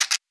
Scratch_v4_wav.wav